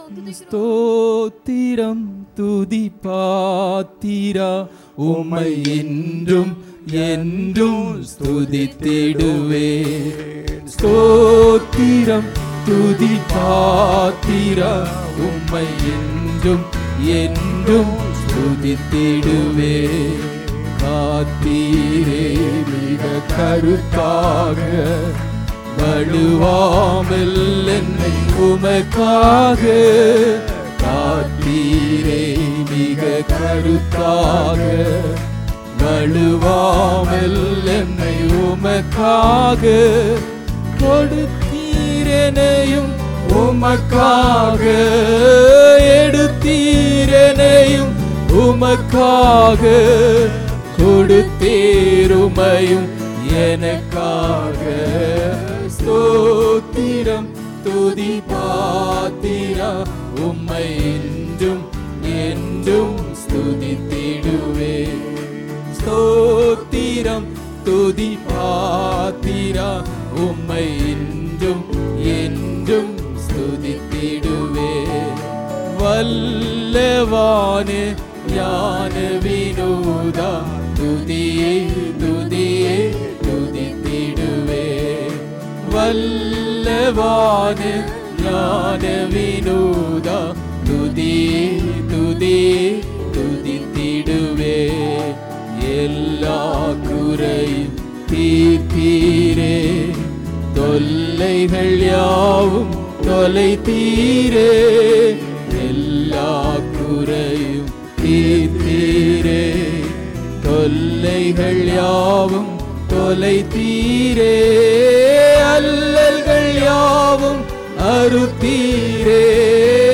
28 Sep 2025 Sunday Morning Service – Christ King Faith Mission